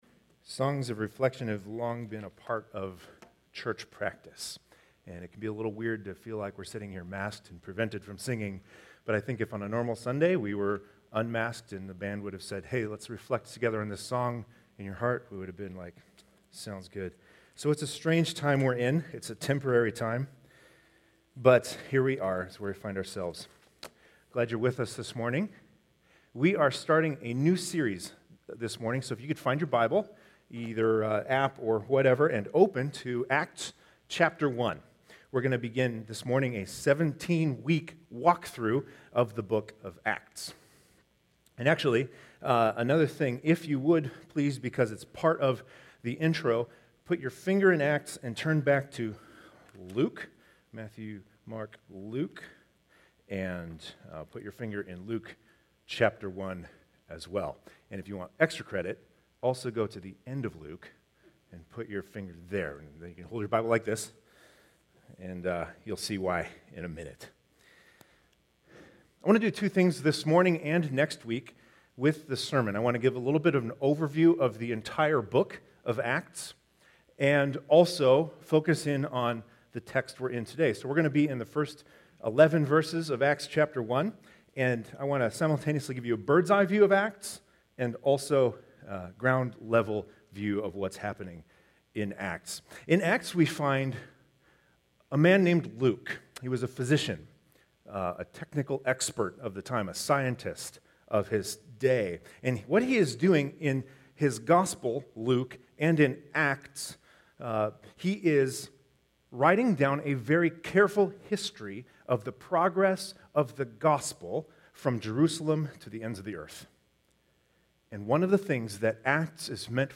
Download sermon manuscript